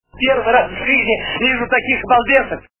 » Звуки » Люди фразы » Из к.ф. - Первый раз вижу таких балбесов
При прослушивании Из к.ф. - Первый раз вижу таких балбесов качество понижено и присутствуют гудки.